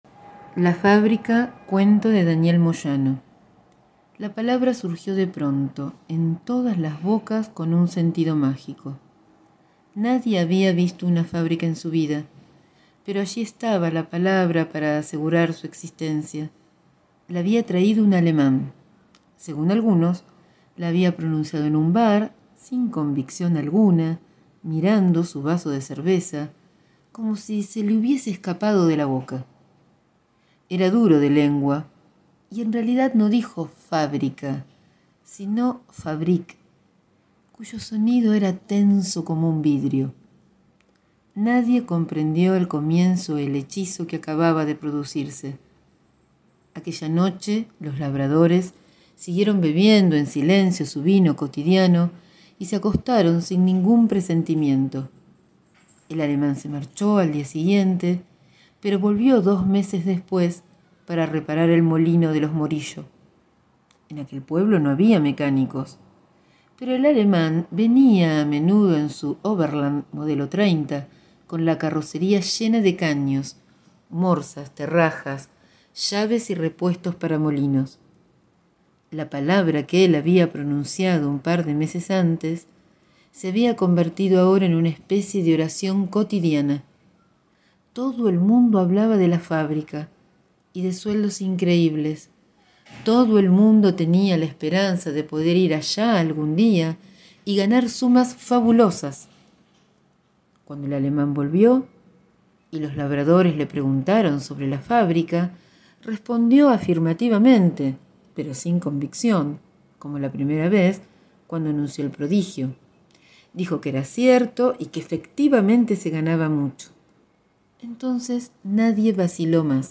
Conversación acerca del cuento "La fábrica" de Daniel Moyano.